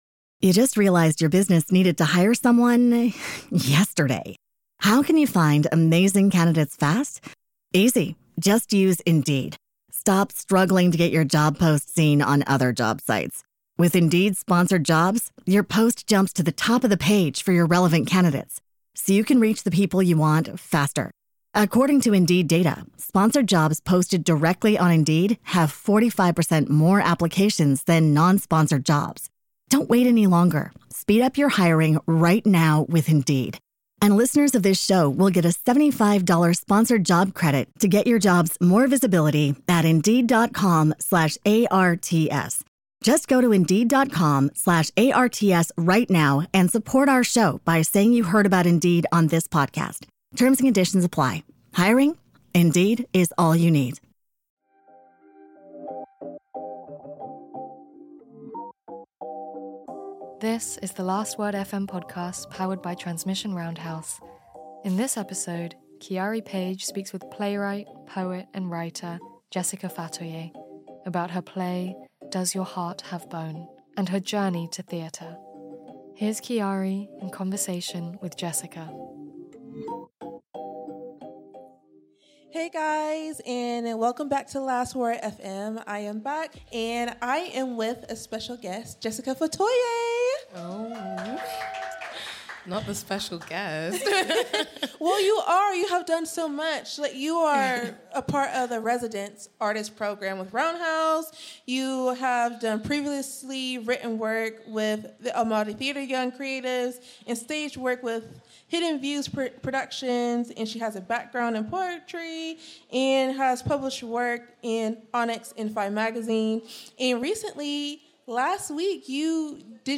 The Last Word FM is an annual live broadcast powered by Transmission Roundhouse with talks, interviews and panels with a number of incredible artists whose work was featured as part of The Last Word Festival.